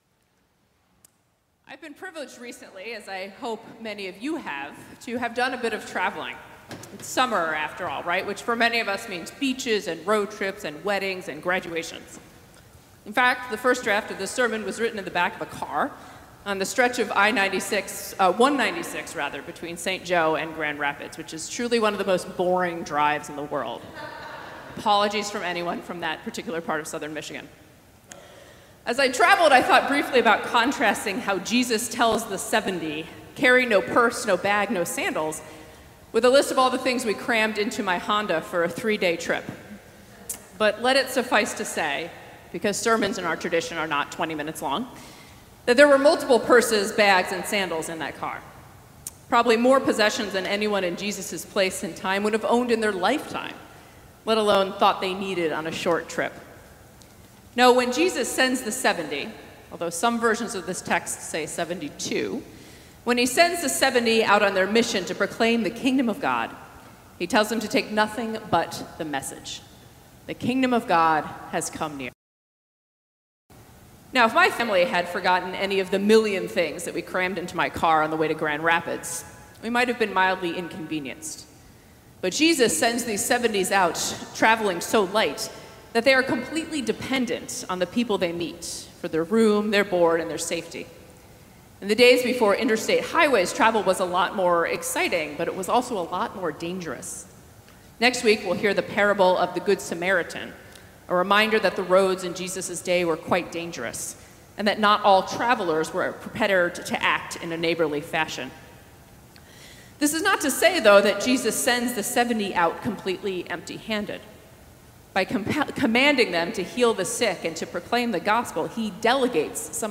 Sermons | Emmanuel Episcopal Church